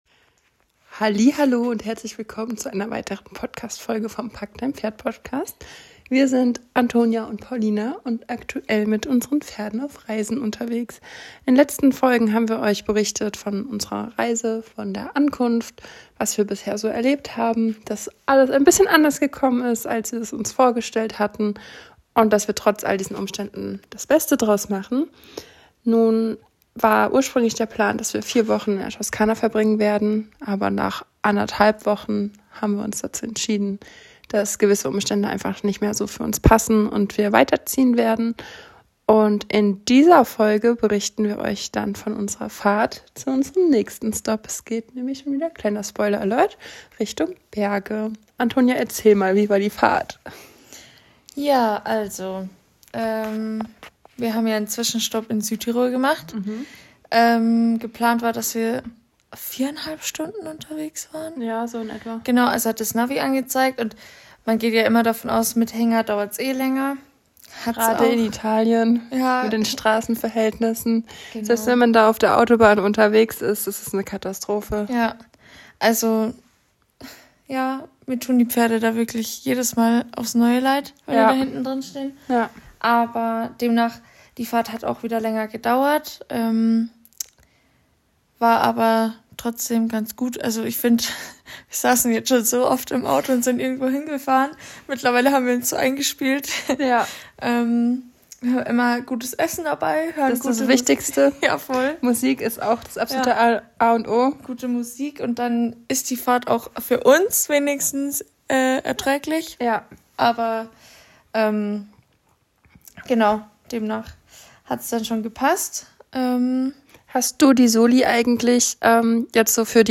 PS: die Aufnahme dieses Podcasts erfolgte sehr spontan im Bett. Daher verzeihe bitte die Tonqualität, wir wollten Dir unsere Erlebnisse dennoch nicht vorenthalten.